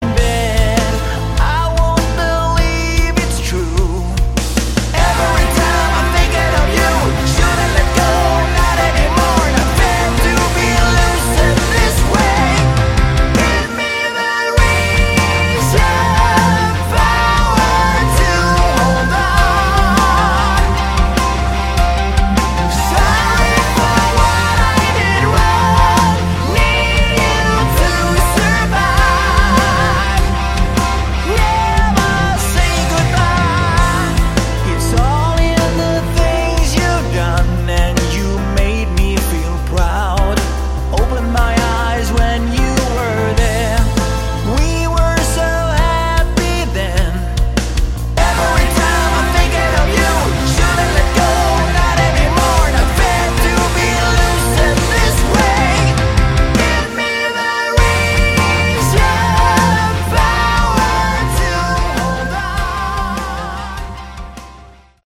Category: Melodic Rock
guitars, keyboards, bass
vocals